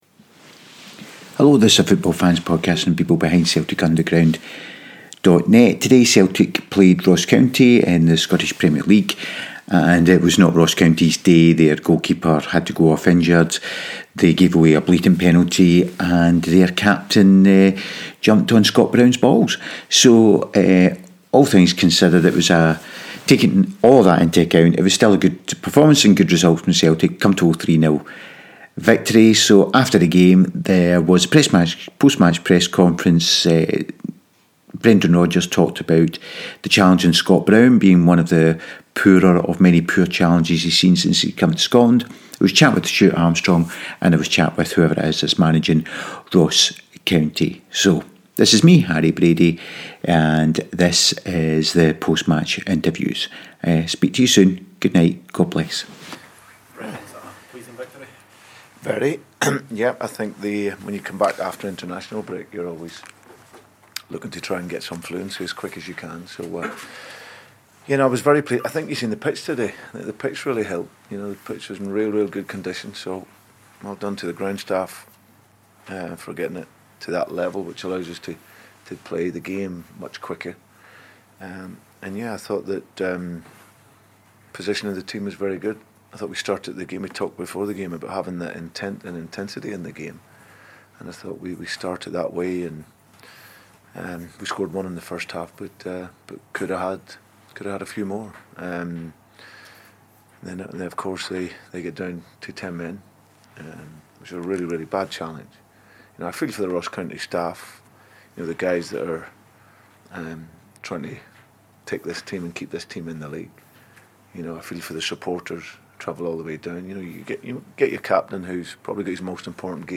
It was all good fun and here is the post match chat.